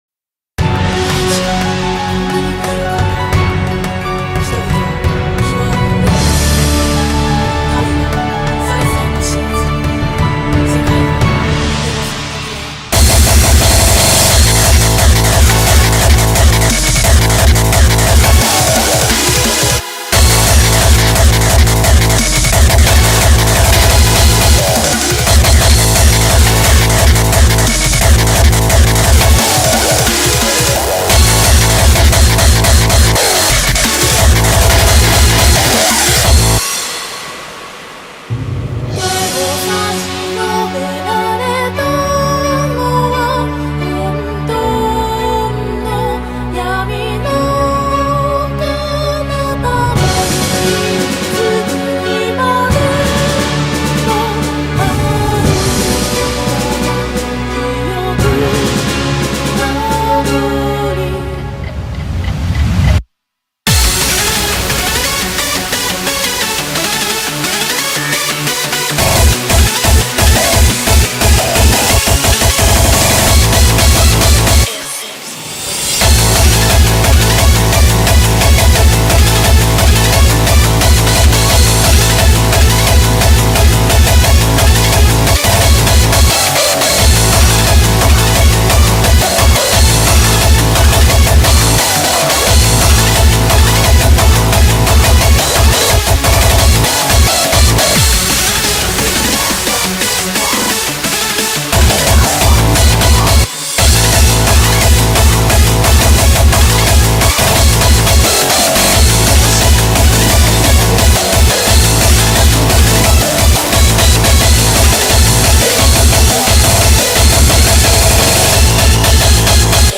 BPM44-175
Audio QualityPerfect (High Quality)
Comments[MAINSTREAM HARDCORE]